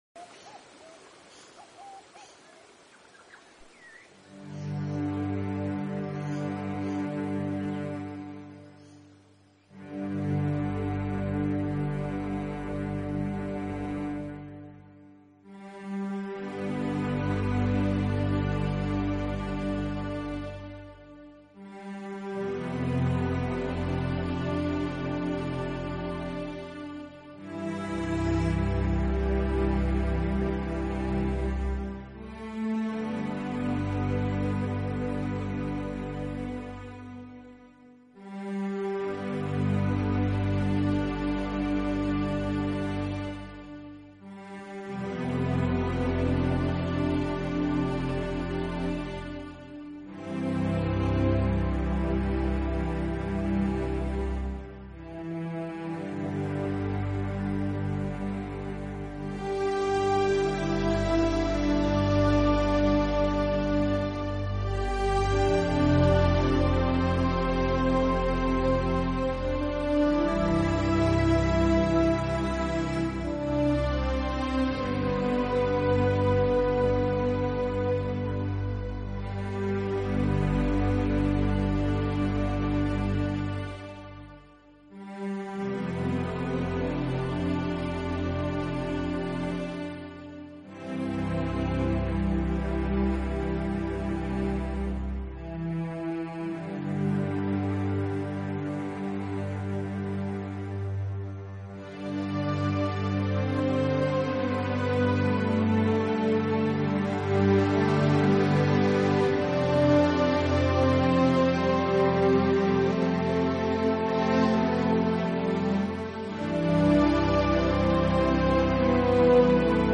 新世纪纯音乐
专辑语言：纯音乐
给与了他灵感就写下了 这张专辑，里面除了有管弦乐外，还加了吉他的长笛，